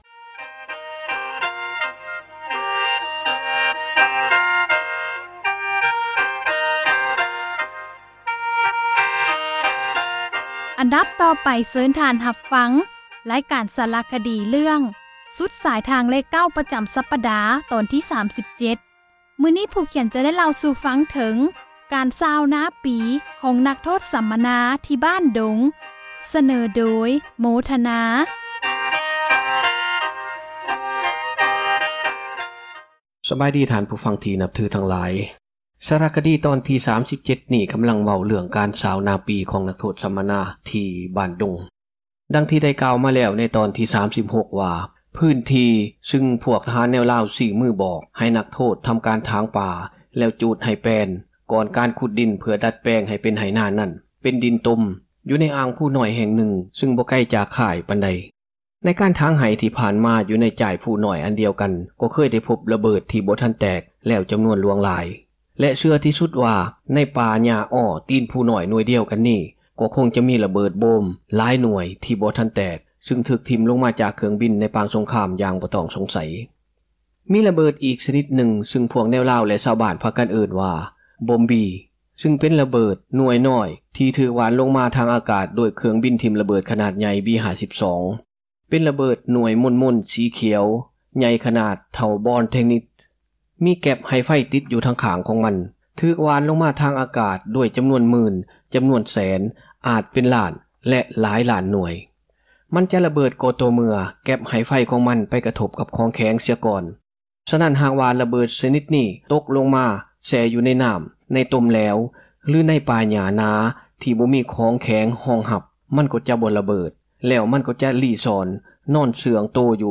ສາຣະຄະດີ ເຣື້ອງ ສຸດສາຍທາງເລຂ 9 ຕອນທີ 37, ຈະໄດ້ເລົ່າເຖິງ ການຊ່າວນາປີ ຂອງນັກໂທດ ສັມມະນາ ຢູ່ ”ບ້ານດົງ”.